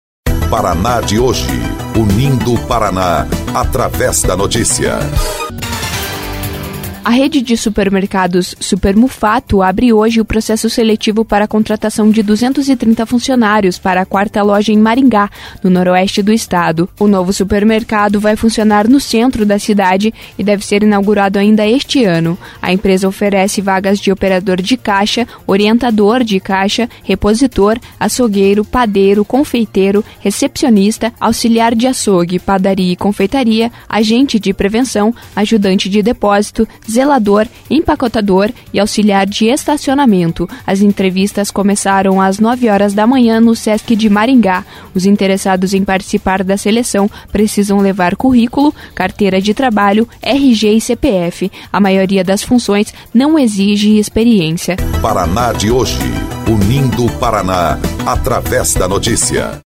19.07 – BOLETIM – Rede de supermercados de Maringá abre processo seletivo para 230 vagas